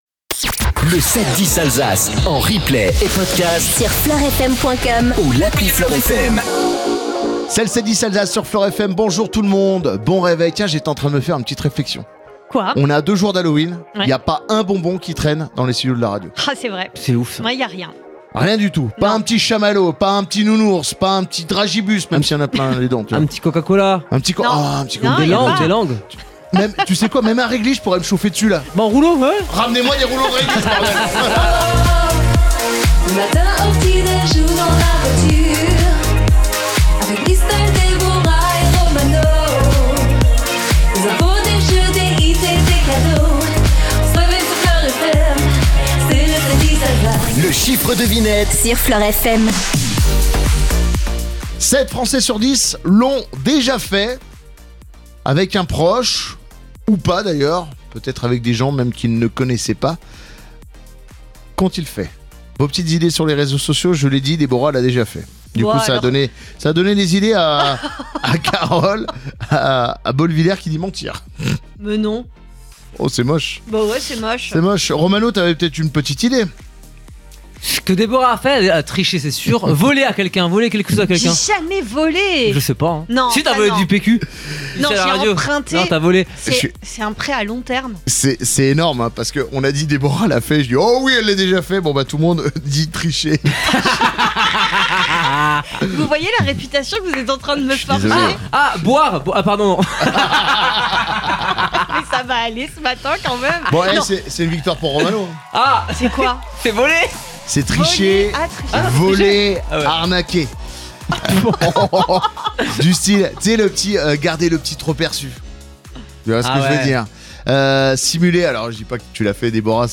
MORNING